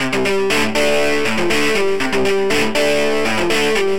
synth guitar
Aj na subtractore sa da nagenerovat umela gitara pri trochu snazeni sa, hoc to znie furt velmi umelo a velmi nie verne...ale nic lepsie synteticke som nepocul :-)
synth_guitar_dist_04.mp3